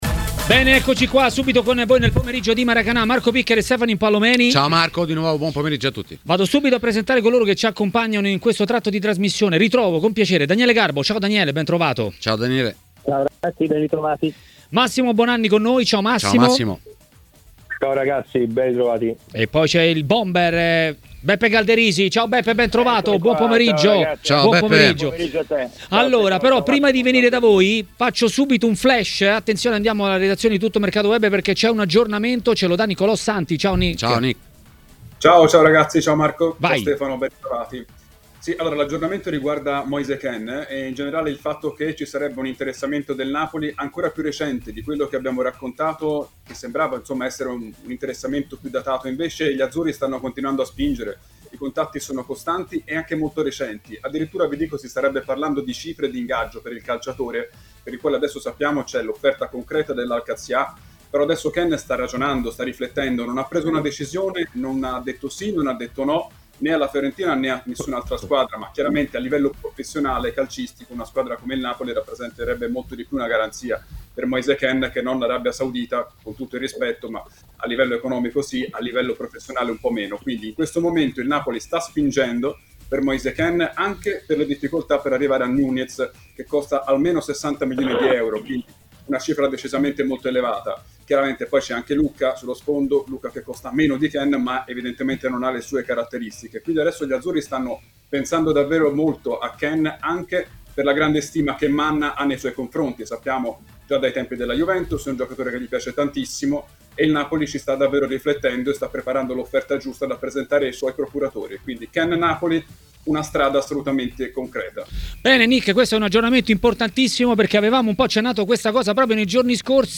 L'ex attaccante e tecnico Giuseppe Galderisi è stato ospite di Maracanà, nel pomeriggio di TMW Radio.